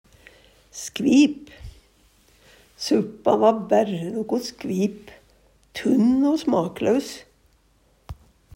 skvip - Numedalsmål (en-US)